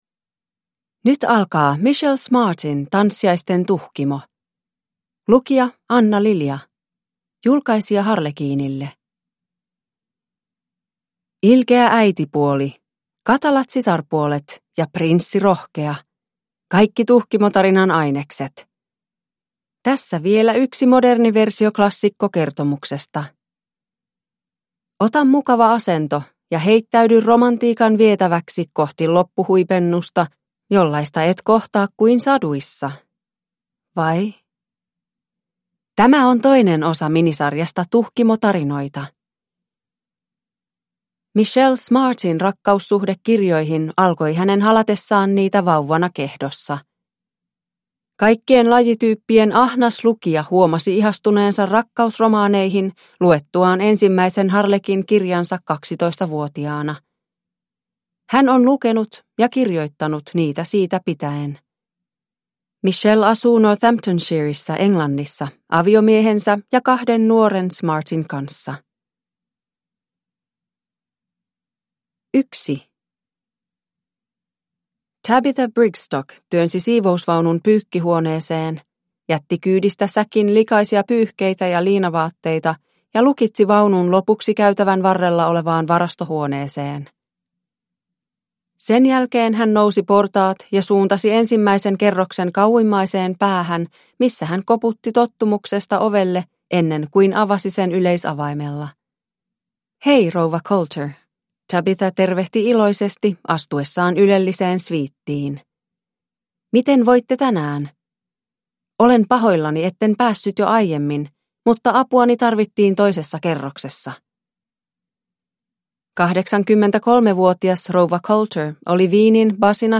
Tanssiaisten Tuhkimo (ljudbok) av Michelle Smart